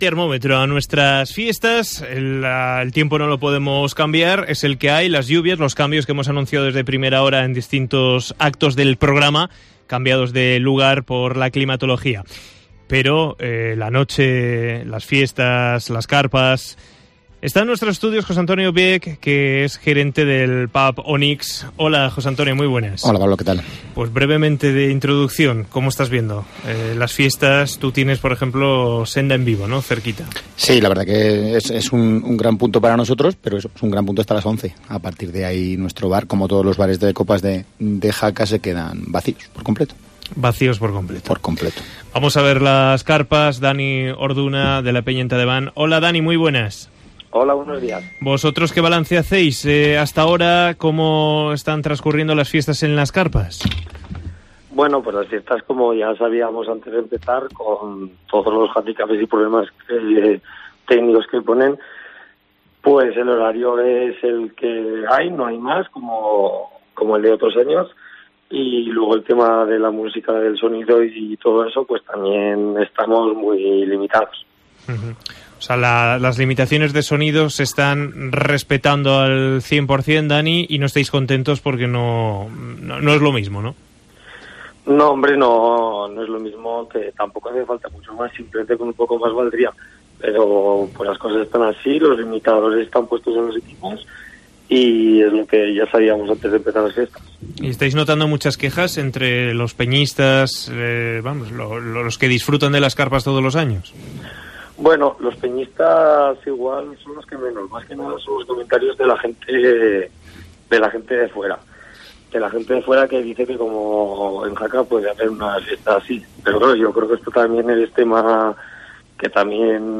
Debate sobre las fiestas de Jaca